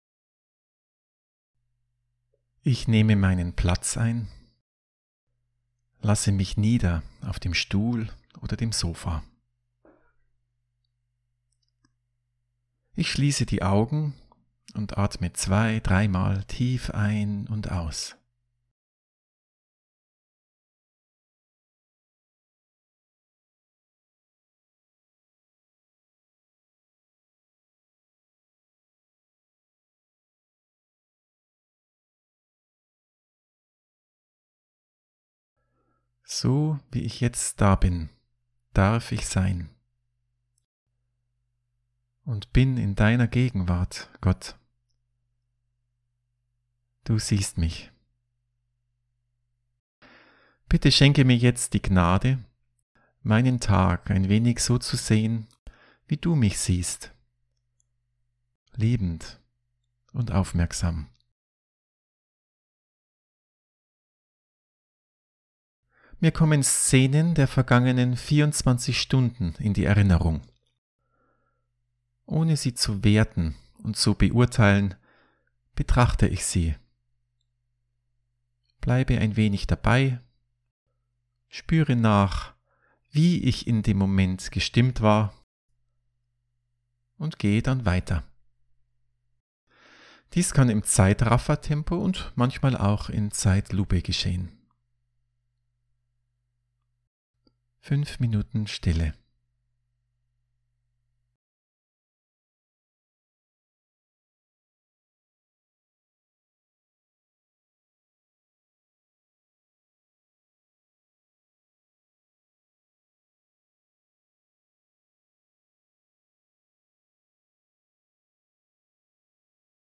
Audio-Anleitung